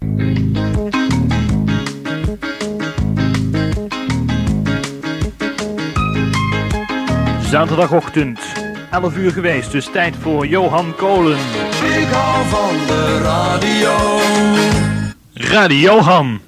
Hier enkele jingles.